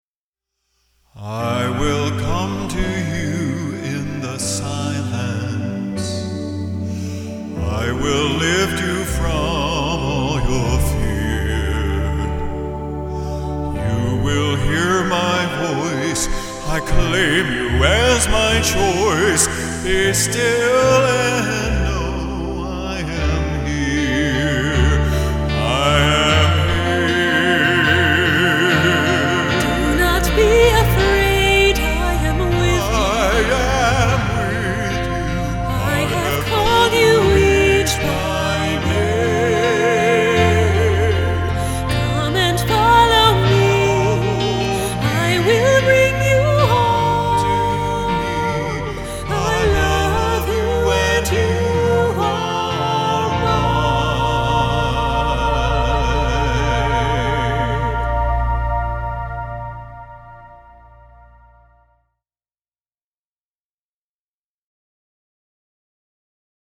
FOR CALLING HOURS AND/OR VIEWING INTO SERVICE: